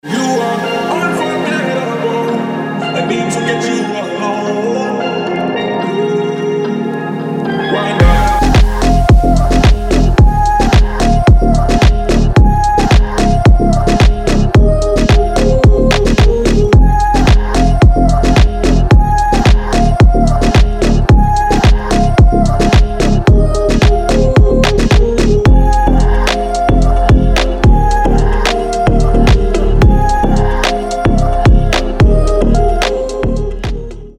• Качество: 320, Stereo
мужской вокал
deep house
dance
Electronic